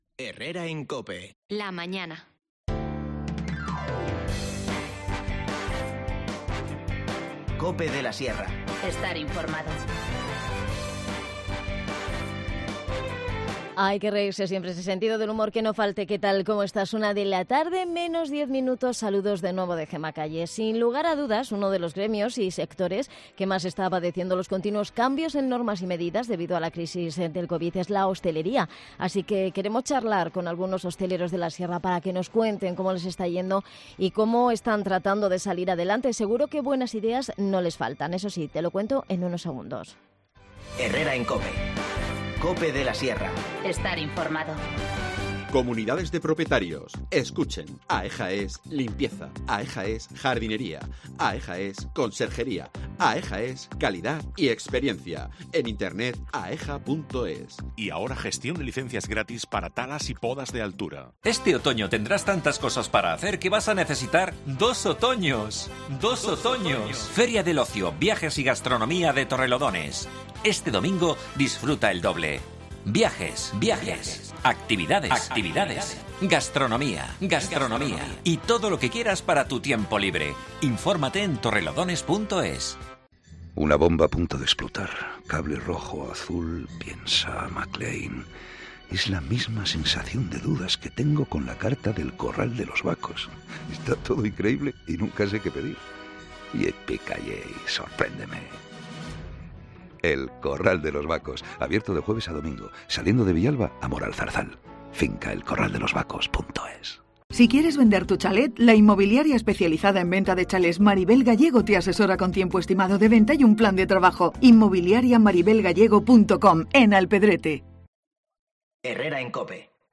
AUDIO: Entrevistamos a algunos hosteleros de la Sierra para que nos cuenten cómo les va y las ideas que han puesto en marcha para que su negocio...